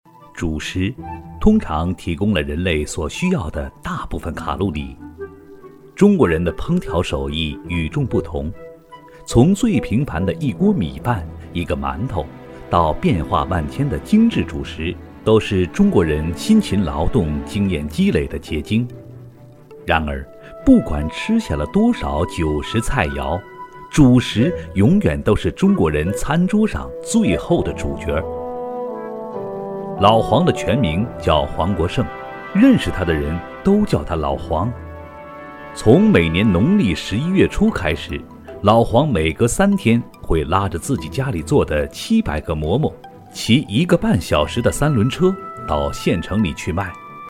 轻松自然 舌尖美食
磁性严肃男音，轻松自然。